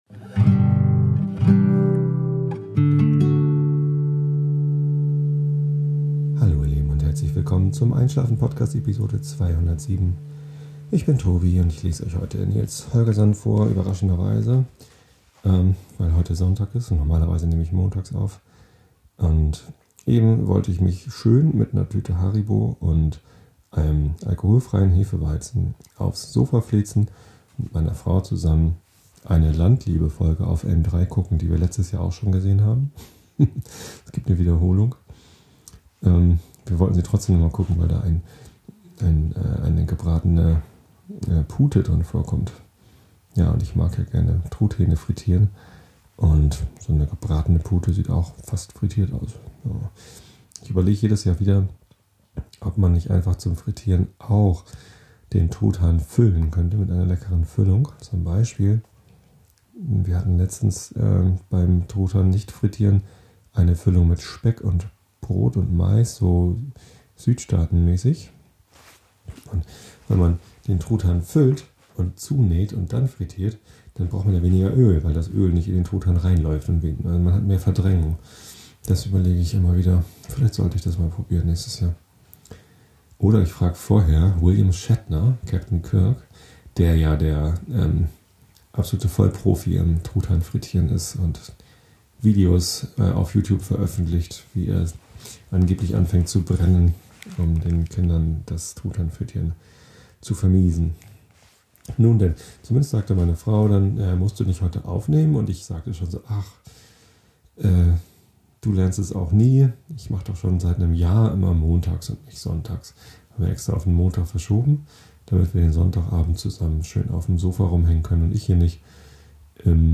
Weil ich etwas in Eile war (hatte das Mikrofon mit bei Muddern, Bericht in dieser Episode), hab ich es beim Wiederaufbau FALSCH HERUM hingestellt und reingesabbelt, und das klingt bei einer Niere natürlich Mist.